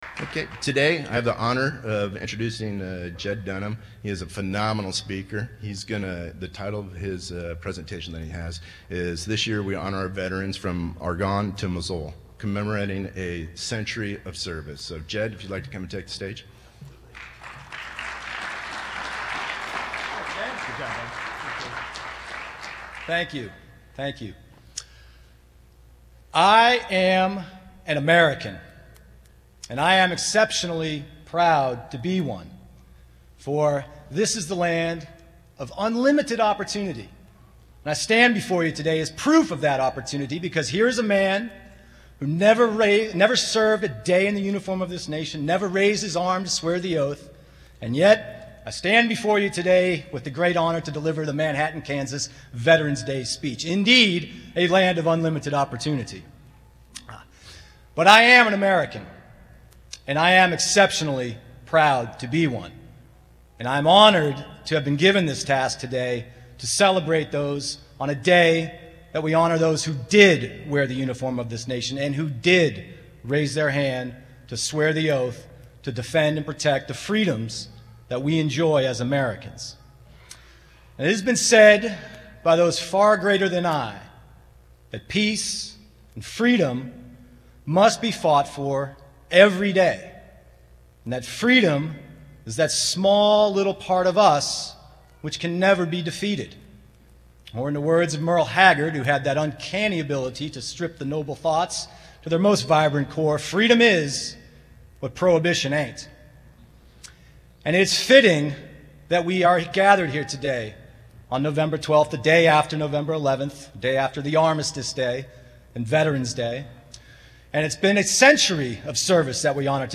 The Manhattan Veterans’ Day Parade organized by the Flint Hills Veterans Coalition was capped off by a ceremony in Peace Memorial Auditorium.